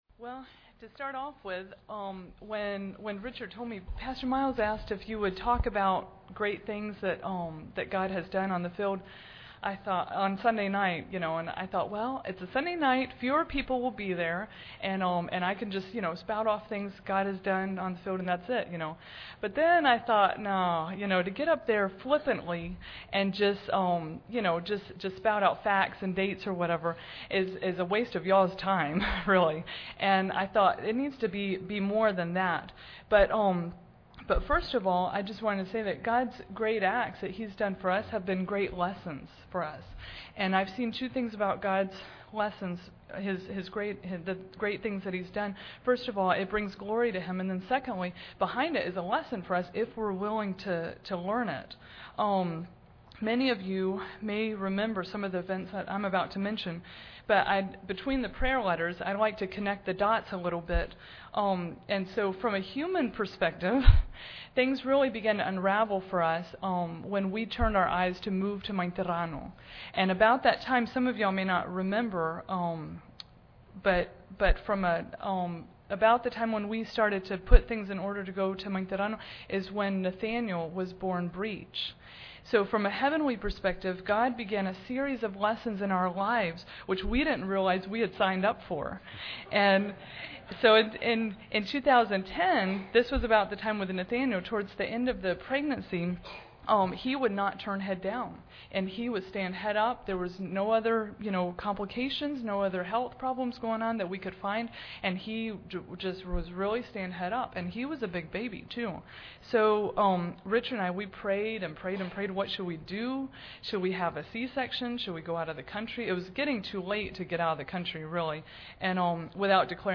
Series: 2012 Missions Conference